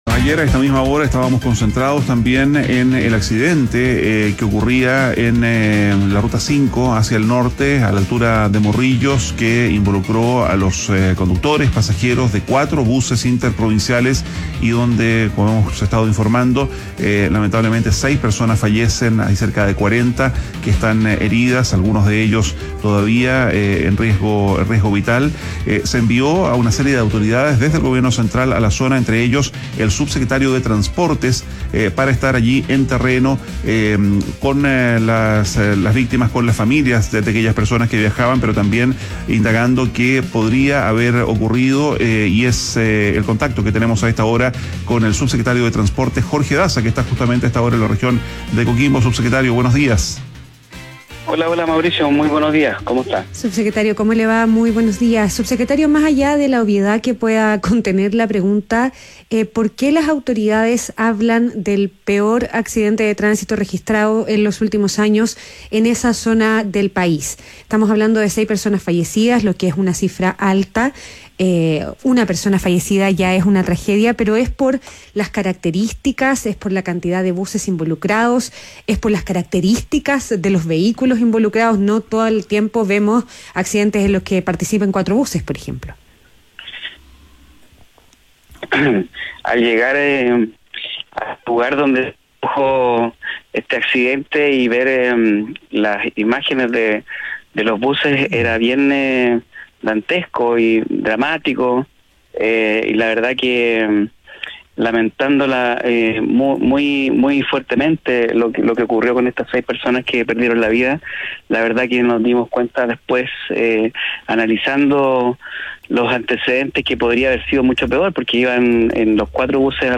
ADN Hoy - Entrevista a Jorge Daza, subsecretario de Transporte